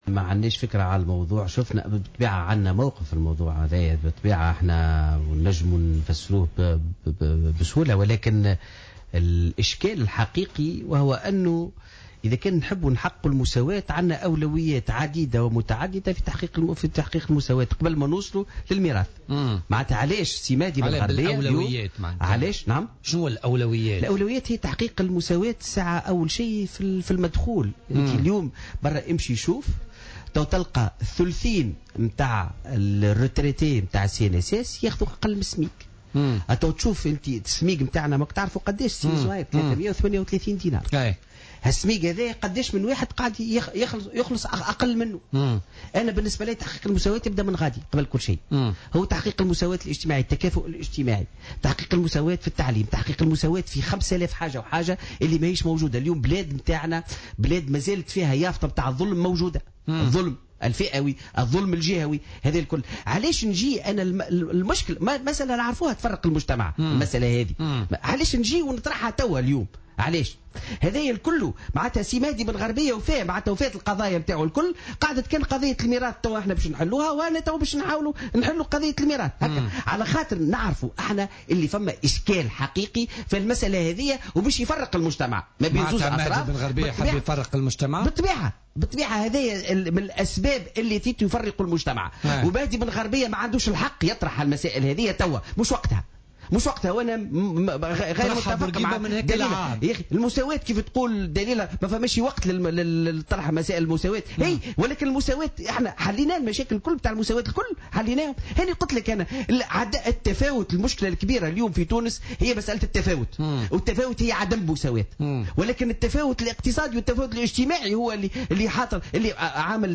وقال في مداخلة على "الجوهرة اف أم" في برنامج "بوليتيكا" إن هذه المبادرة هي "كلمة حق أريد بها باطل" مضيفا أن الأمر يعتبر "قلة مسؤولية" من طرف النائب.